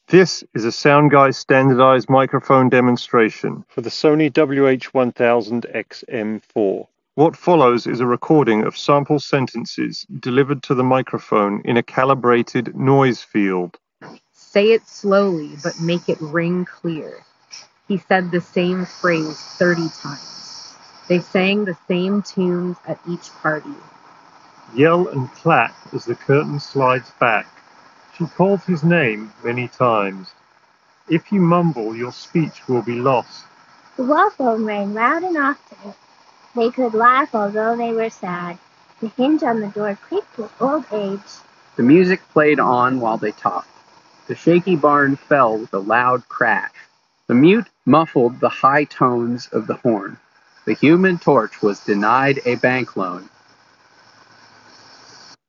Sony-WH-1000xm4_Street-microphone-sample.mp3